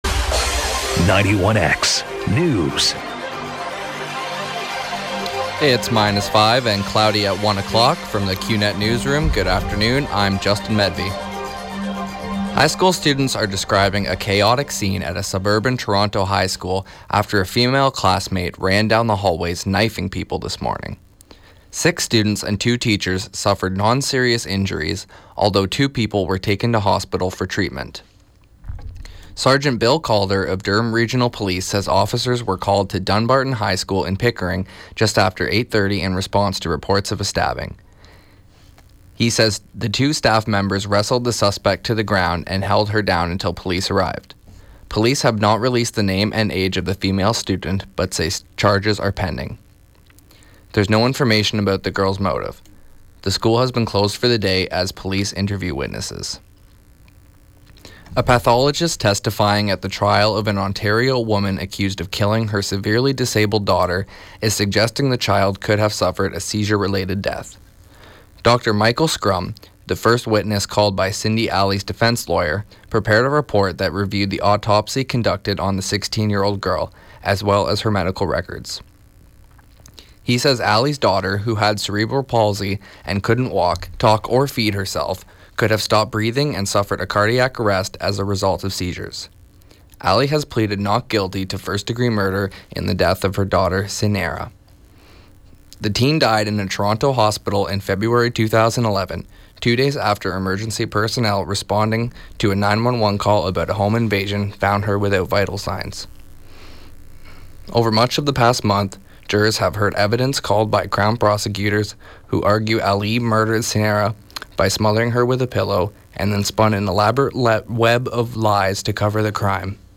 91X newscast – Tuesday, Feb. 23, 2016 –1 p.m.